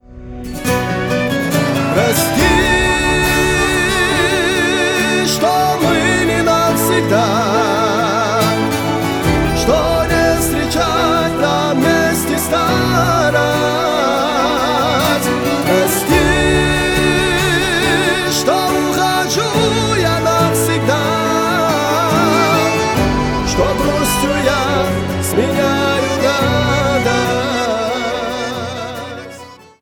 Шансон
грустные
кавказские